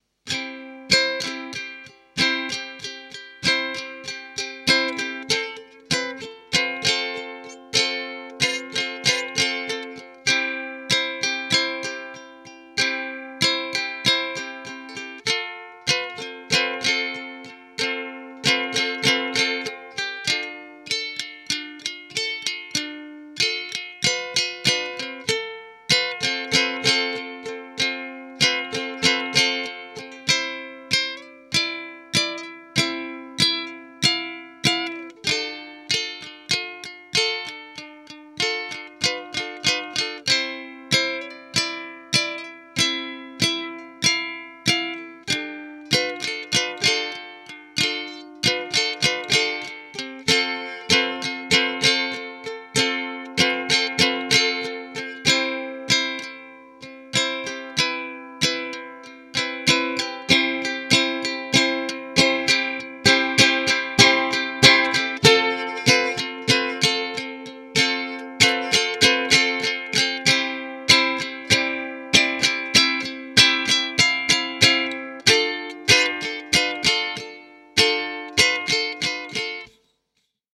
ukululu.mp3